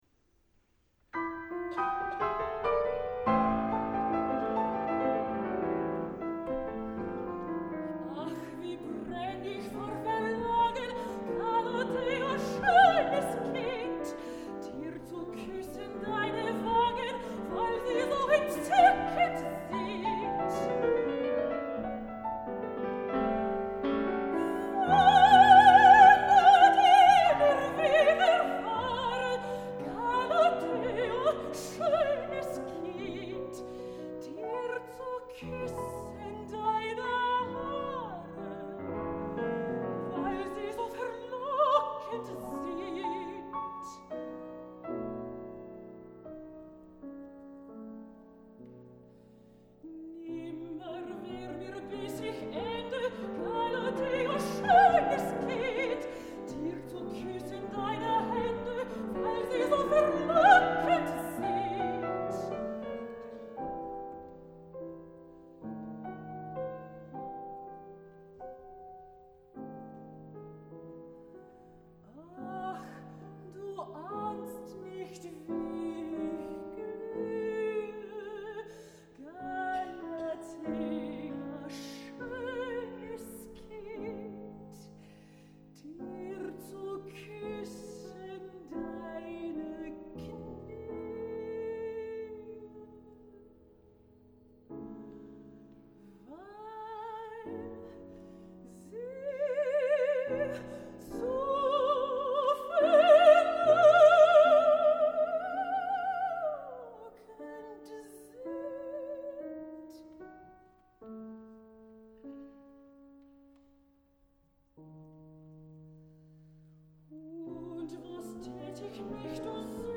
piano
Recorded at Sejii Ozawa Hall, Tanglewood